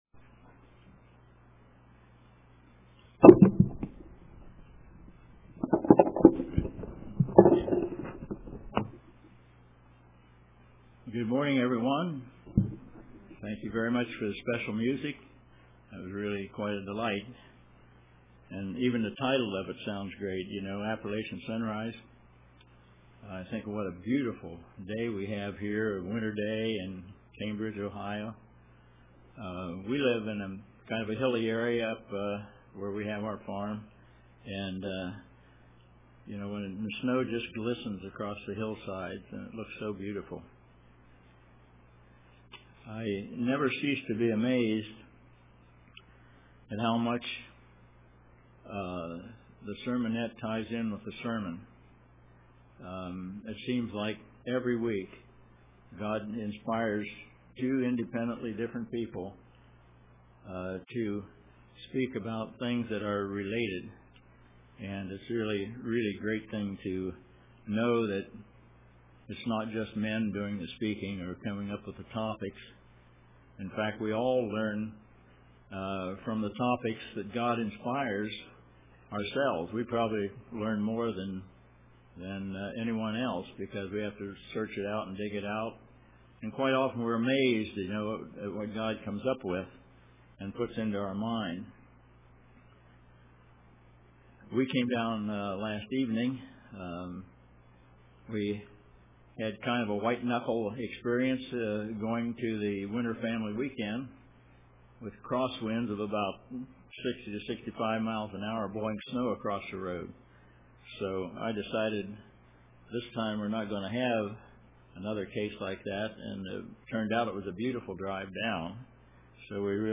Given on 01/05/2013 UCG Sermon Studying the bible?